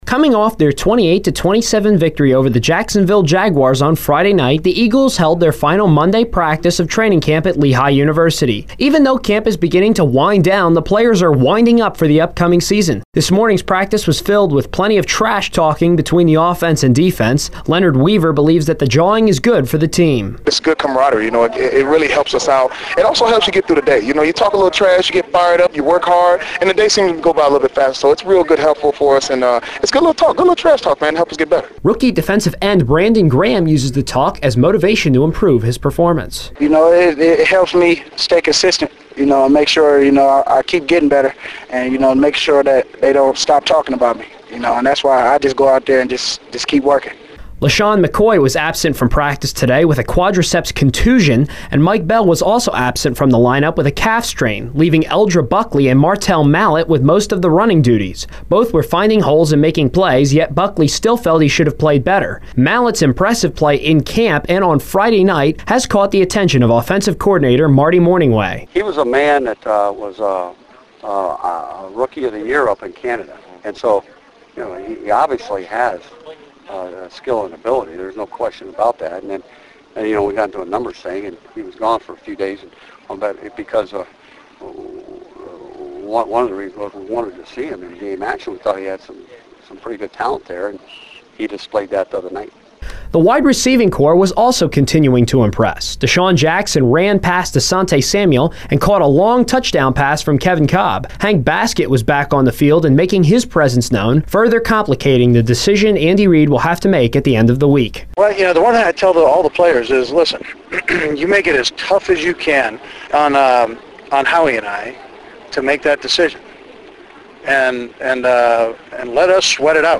The reports included audio I gathered from interviews with both players and coaches.